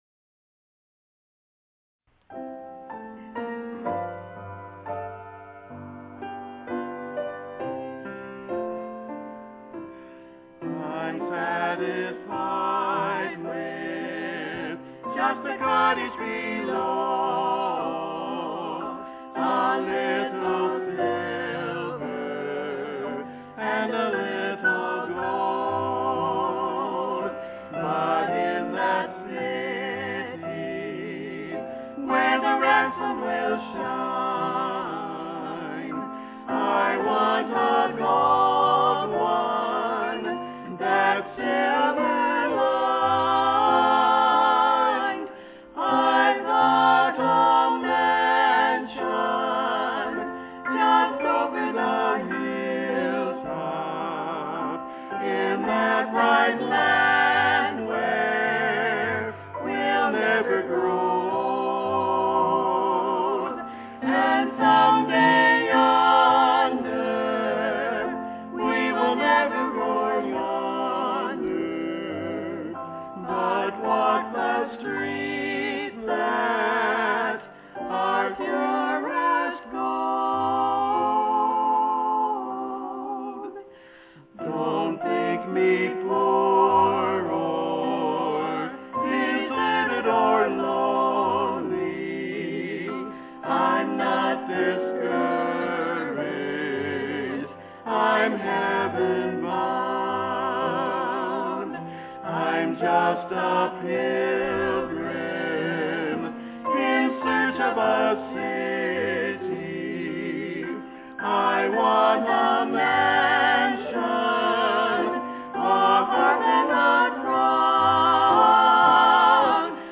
Sunday PM Music - July 24, 2011
Trio